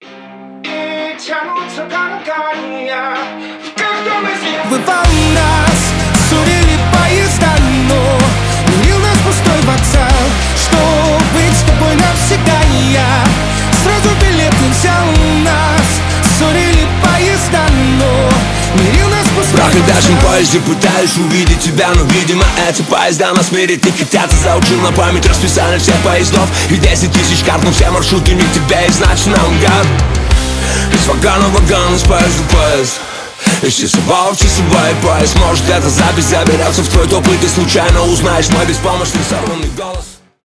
гитара
рок
рэп